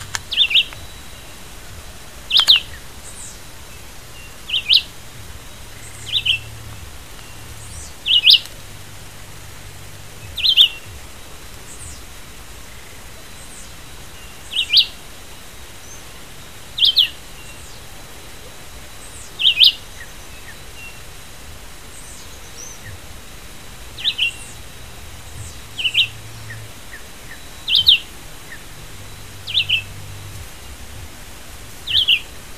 Chivi Vireo (Vireo chivi)
Life Stage: Adult
Country: Brazil
Location or protected area: União dos Palmares
Condition: Wild
Certainty: Photographed, Recorded vocal